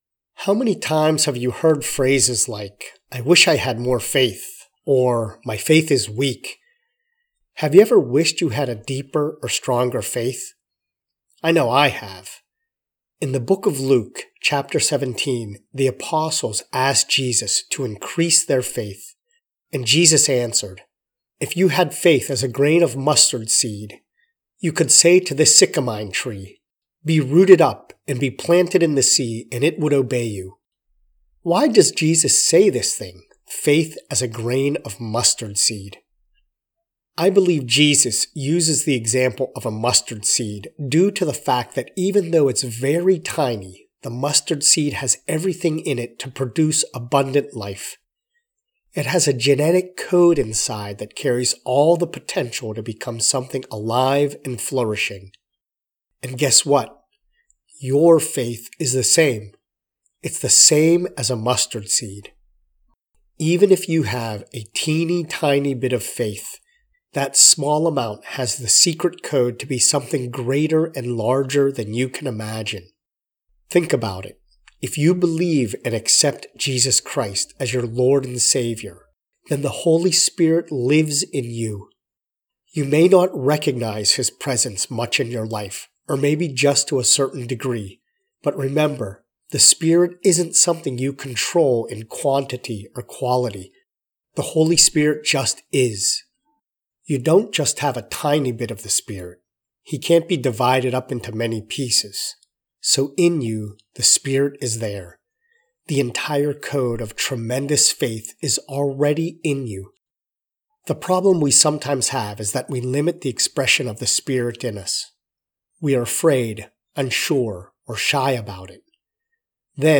A prayer that helps people to deepen their faith in God by reading his Word, prayer and trusting.